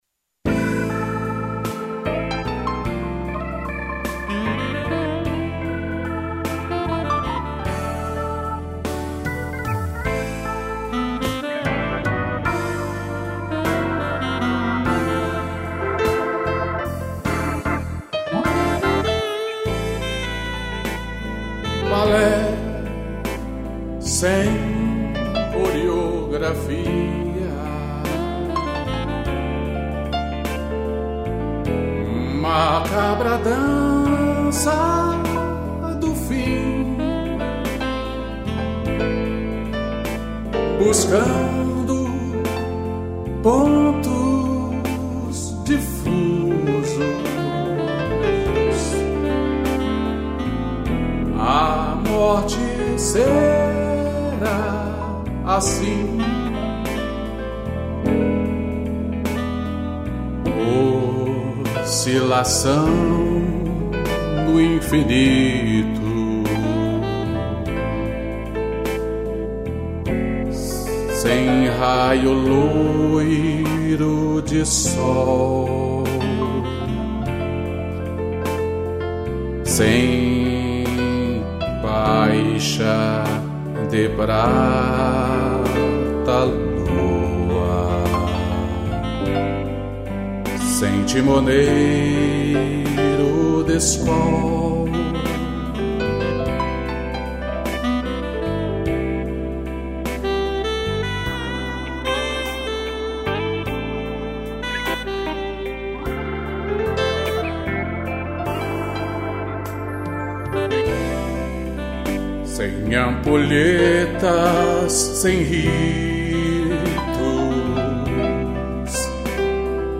piano e sax